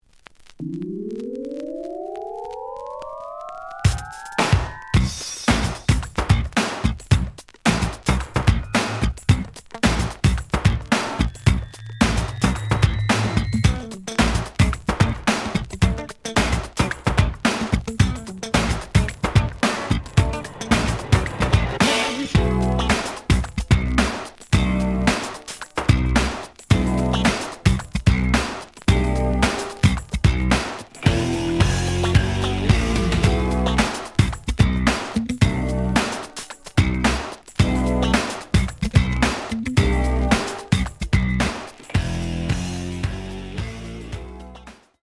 The audio sample is recorded from the actual item.
●Genre: Funk, 80's / 90's Funk
Edge warp.